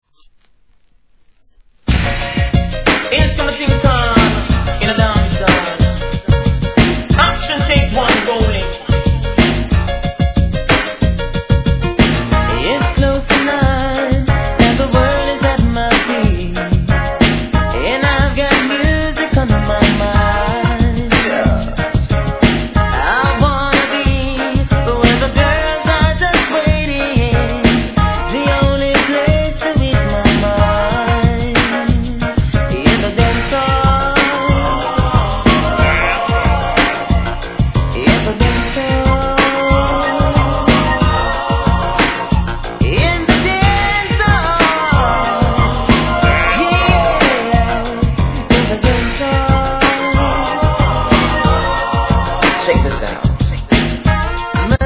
REGGAE
軽快なDANCEHALL RHYTHM!!